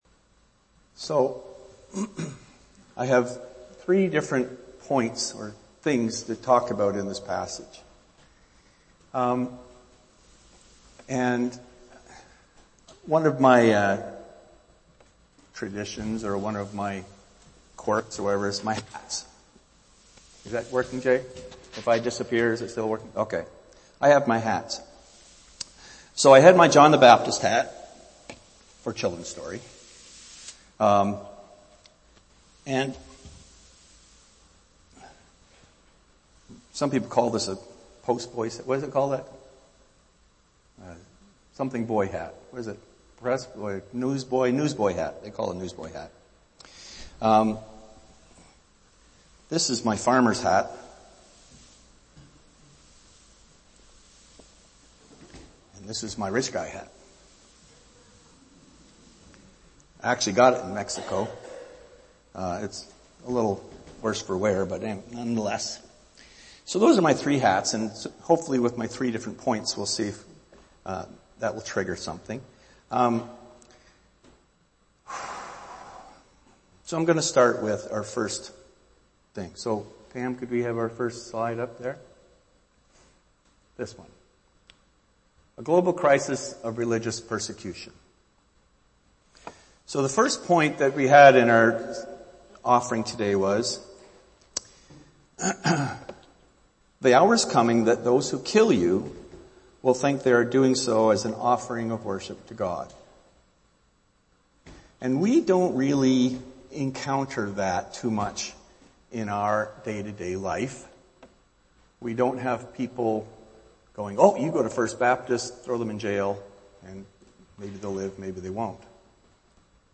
Archived Sermons